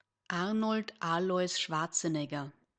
2. ^ Pronounced /ˈʃwɔːrtsənɛɡər/ SHWORT-sə-neg-ər; Austrian German: [ˈarnɔld ˈaːlɔʏs ˈʃvartsn̩ˌɛɡɐ]